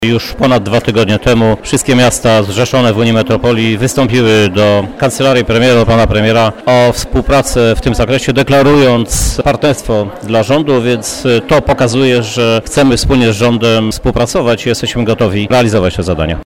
• mówi prezydent Krzysztof Żuk.